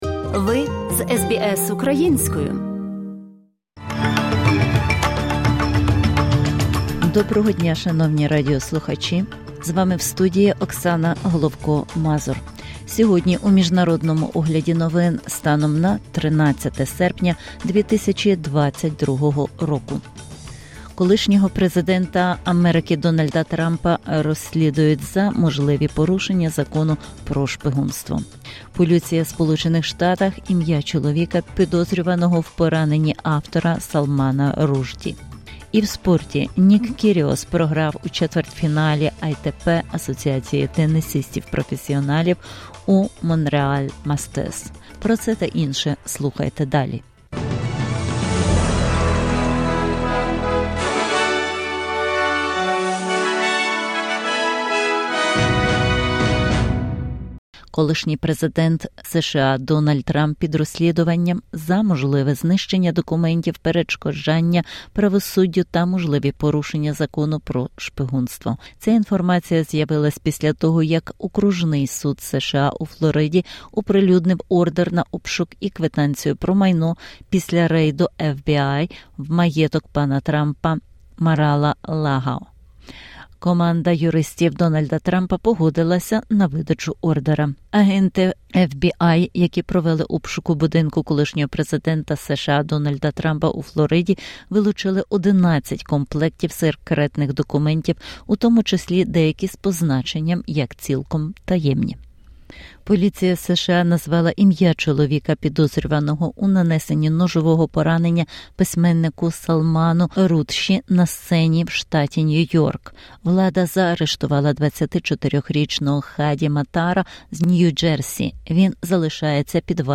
[ OM ] SBS News in Ukrainian - 13/08/2022